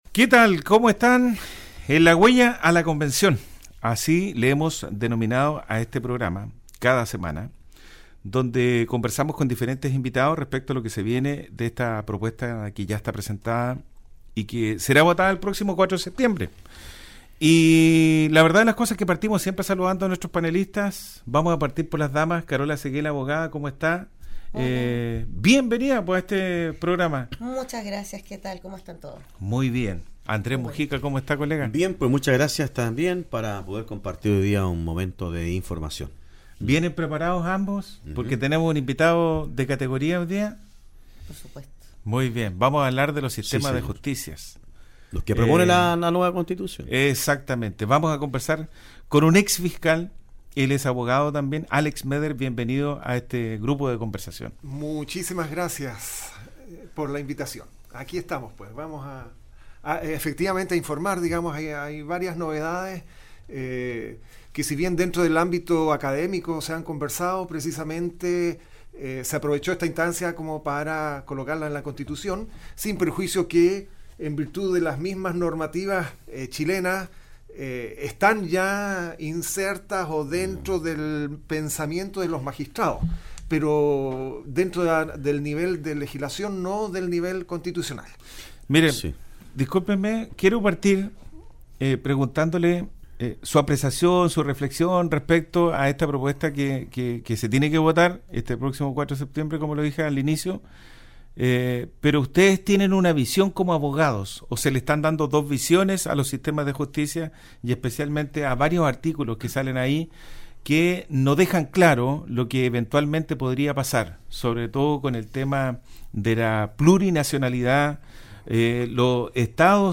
Conversación que nació durante el tiempo que funcionó la Convención Constituyente encargada de confeccionar la Nueva Constitución para Chile, terminado ese proceso, el análisis y las entrevistas continúan, ahora en vistas al Plebiscito de salida.